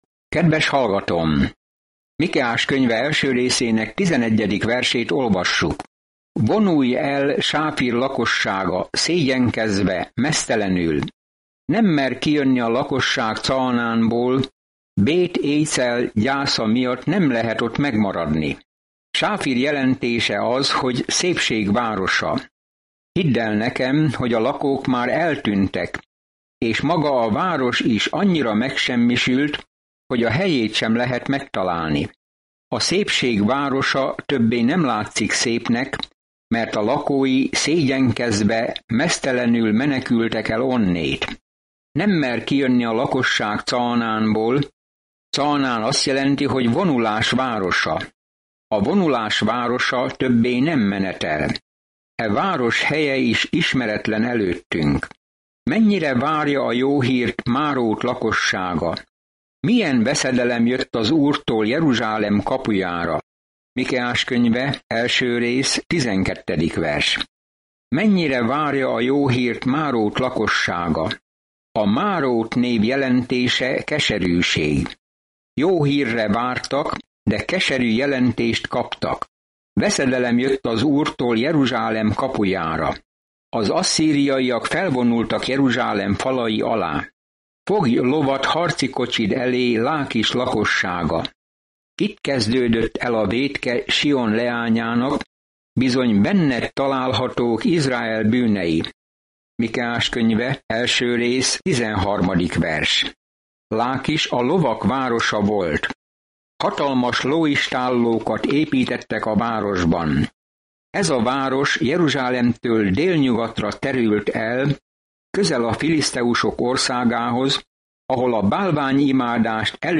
Szentírás Mikeás 1:11-16 Mikeás 2:1-3 Nap 2 Olvasóterv elkezdése Nap 4 A tervről Mikeás gyönyörű prózában felszólítja Izrael és Júda vezetőit, hogy szeressék az irgalmasságot, cselekedjenek igazságosan és járjanak alázatosan Istennel. Napi utazás Mikeán, miközben hallgatod a hangos tanulmányt, és olvasol válogatott verseket Isten szavából.